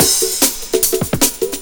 PERCLOOP1-L.wav